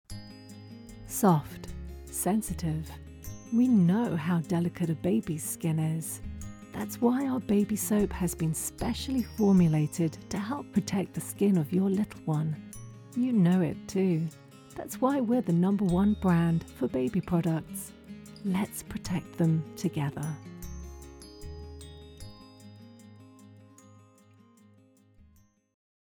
Female
Radio / TV Imaging
A Voice Demo Showcasing A Bright, Cheerful, Warm, Friendly, And Relatable Tone.